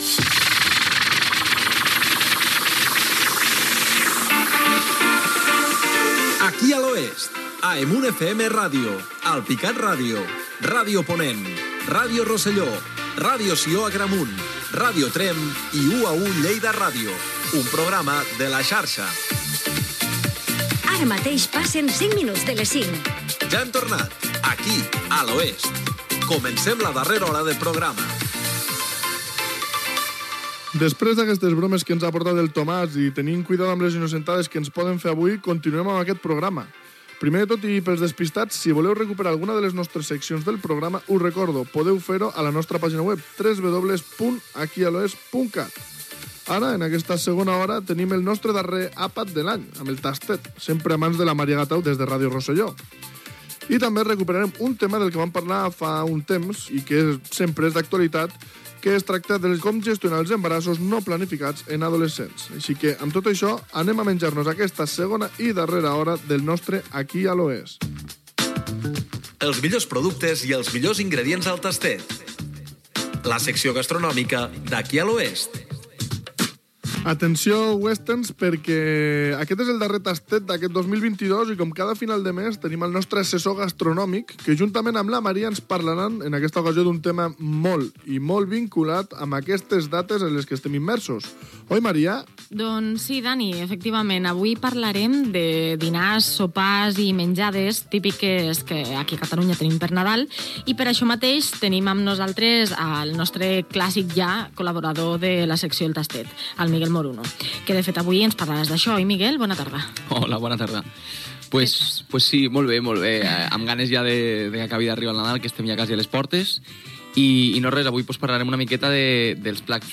Careta amb les emissores que emeten el programa: Ràdio Tremp, Ràdio Sió Agramunt, EMUN FM Ràdio, Ràdio Rosselló, Ràdio Ponent Mollerussa, Ràdio Alpicat i UA1 Lleida Ràdio.
Entreteniment